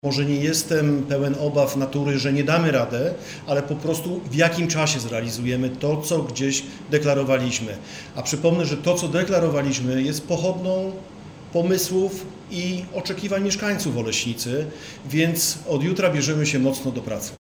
– Taka jest natura ludzka, że wszystko, co nowe budzi obawy – mówi Adam Horbacz, burmistrz Oleśnicy.